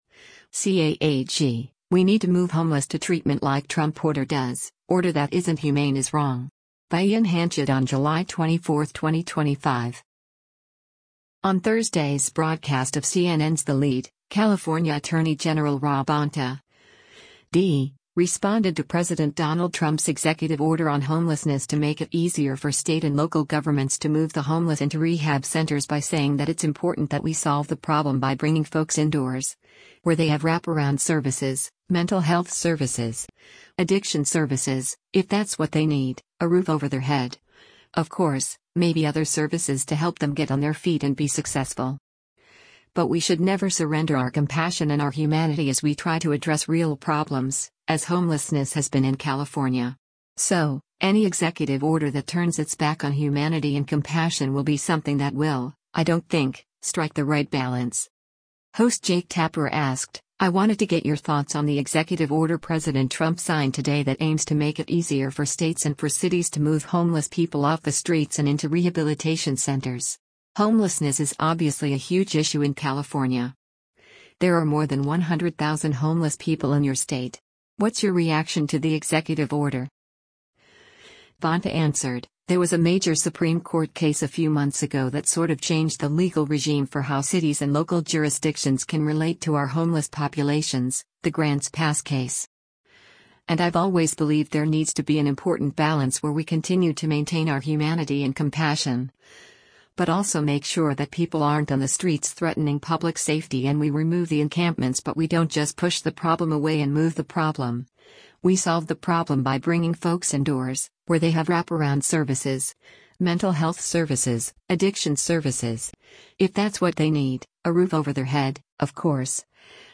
On Thursday’s broadcast of CNN’s “The Lead,” California Attorney General Rob Bonta (D) responded to President Donald Trump’s executive order on homelessness to make it easier for state and local governments to move the homeless into rehab centers by saying that it’s important that “we solve the problem by bringing folks indoors, where they have wraparound services, mental health services, addiction services, if that’s what they need, a roof over their head, of course, maybe other services to help them get on their feet and be successful.